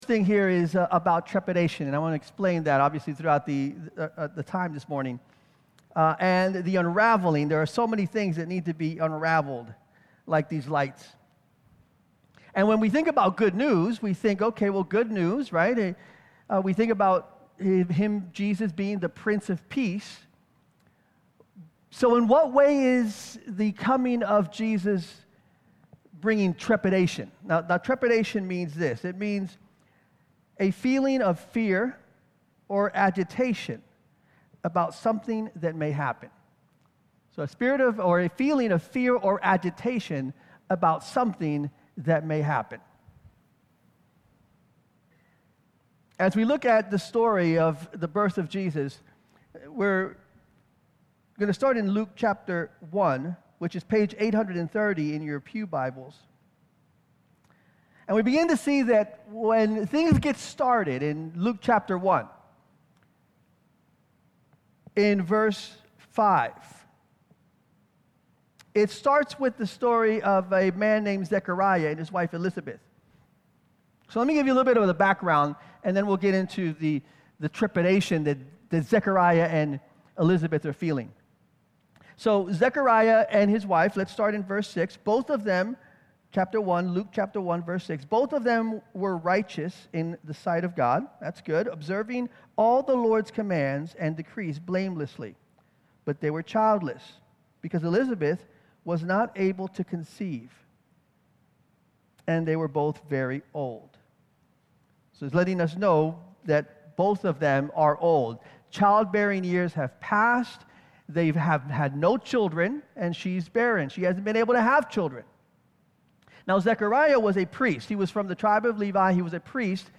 Sermon Archives | Syracuse Alliance Church